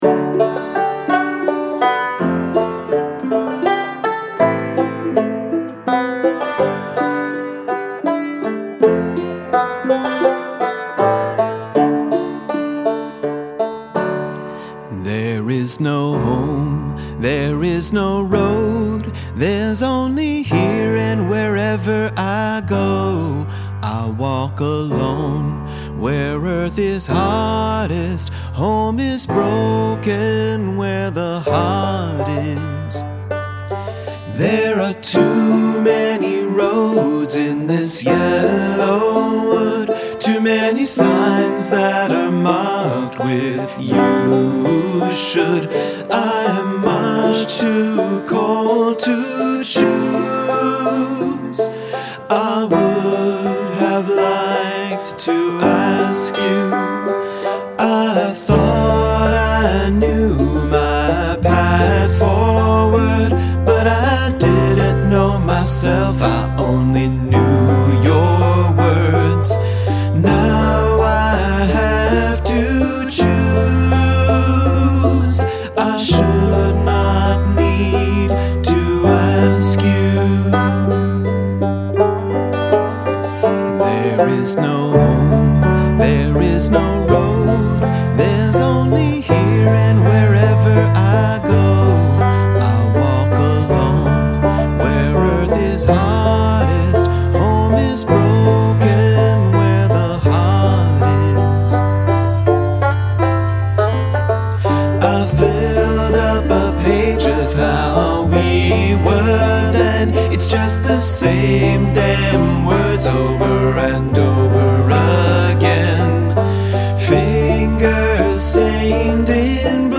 Polyrhythm
I love the banjo!